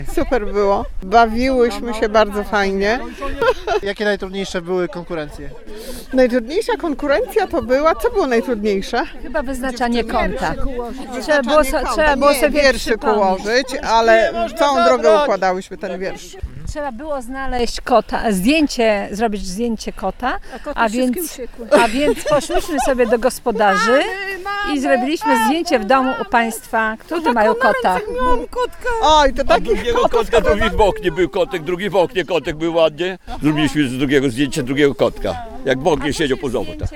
Mówili uczestnicy „Babskiego Walkingu”.